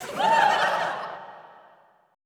LAUGHTER 1-R.wav